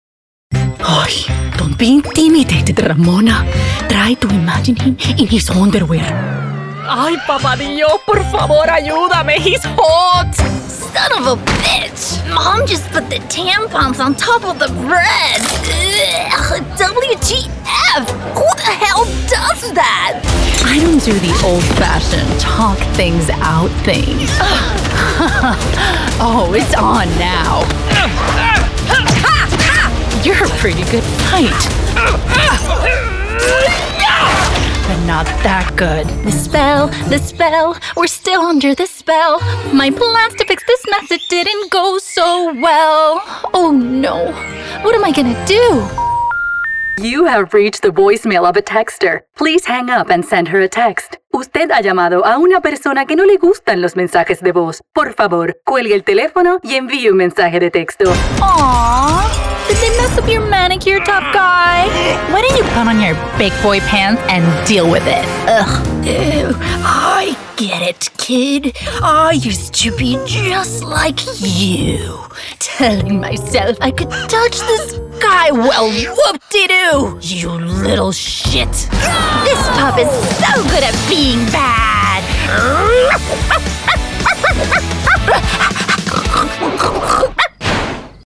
Animation